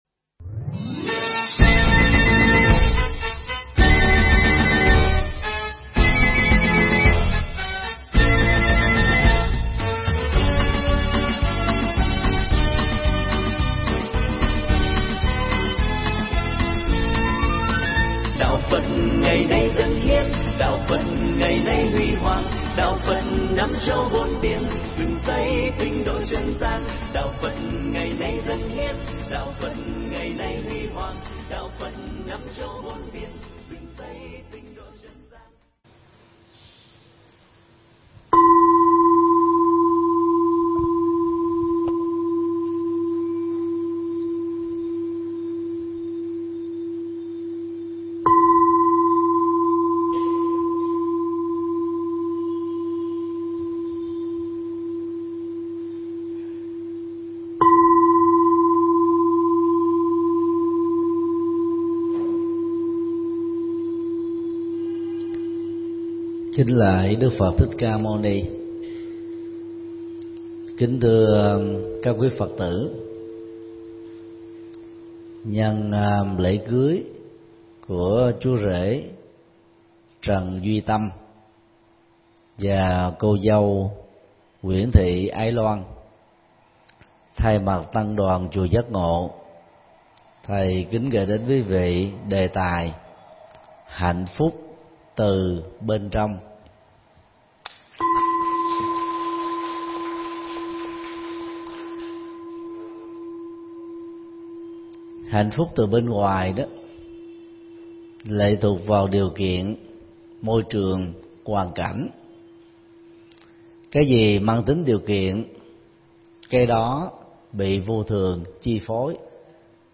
Mp3 Thuyết Giảng Hạnh Phúc Từ Bên Trong
giảng tại Chùa Giác Ngộ